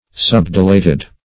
Subdilated \Sub`di*lat"ed\, a. Partially dilated.